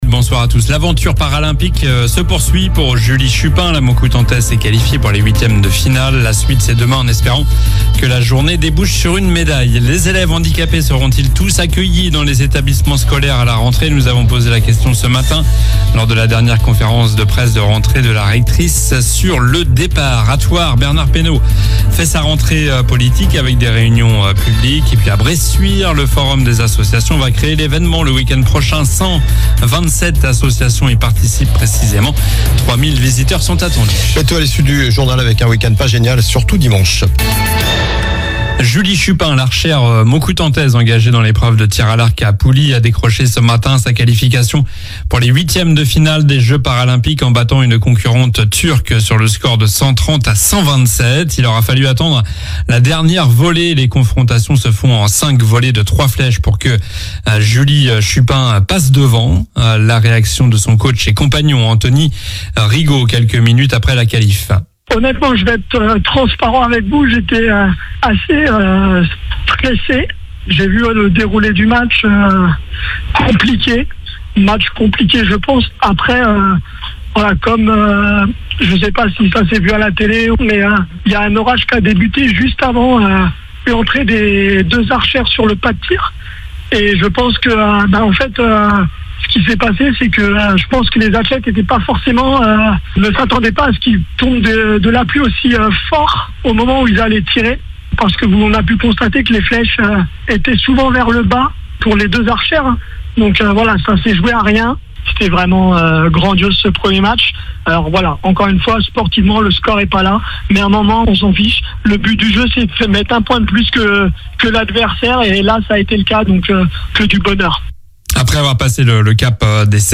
Journal du vendredi 30 août (soir)
L'info près de chez vous